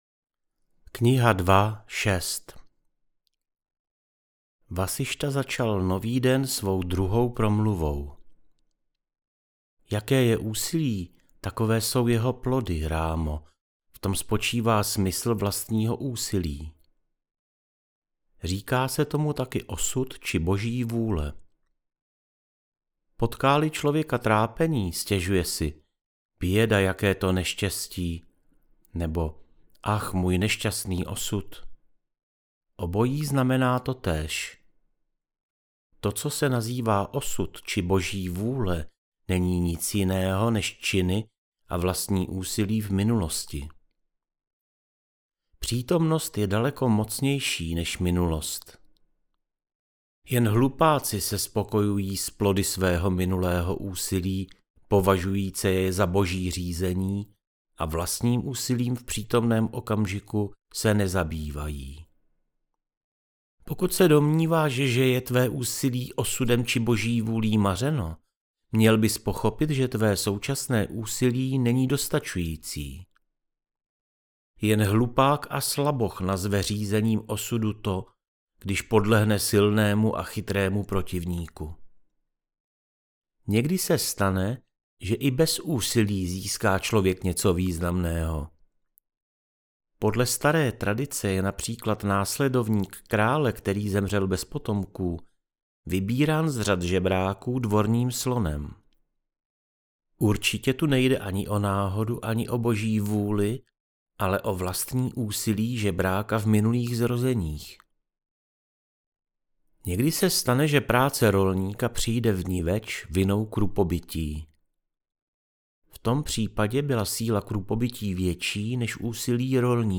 JÓGA VÁSIŠTHA - AUDIOKNIHA